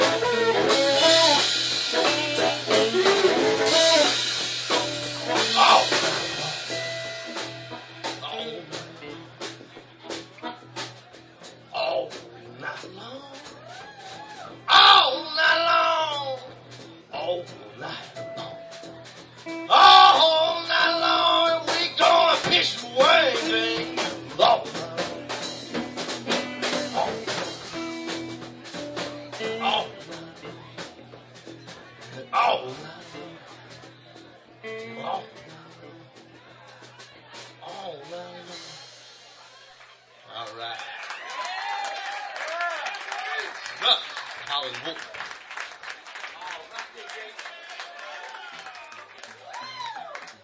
5th OTS Recital - Winter 2005 - rjt_4136